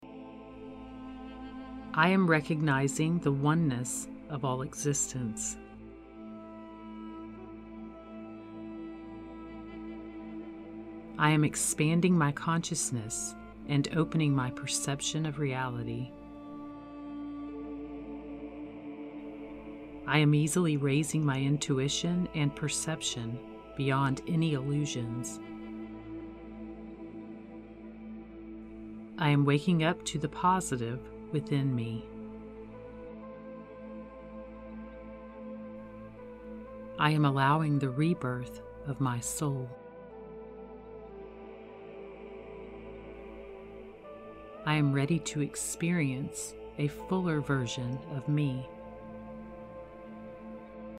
The affirmations have been combined with an Ajna Chakra (Third Eye Chakra) music track specifically produced to increase intuition, expand consciousness and enhance deeper meaning and truth.
spiritual-awakening-affirmations-sample.mp3